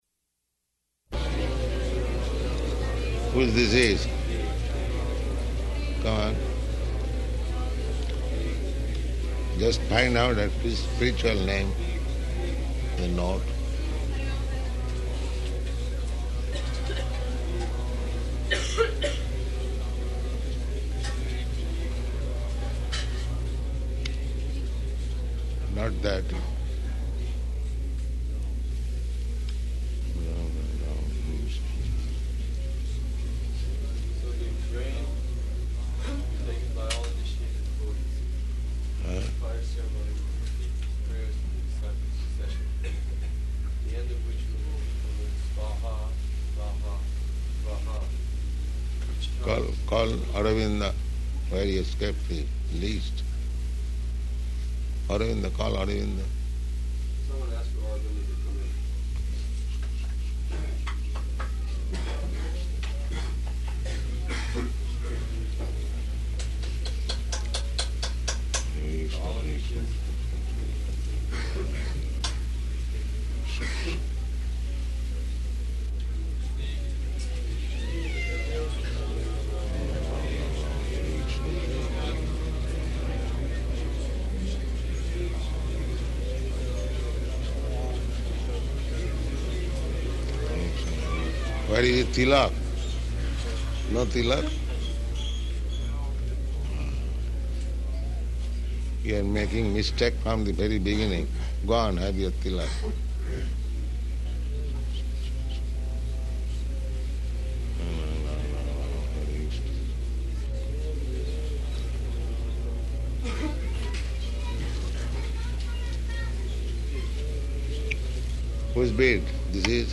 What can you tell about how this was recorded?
Initiations --:-- --:-- Type: Initiation Dated: July 17th 1971 Location: Detroit Audio file: 710717IN-DETROIT.mp3 Devotees: [ japa ].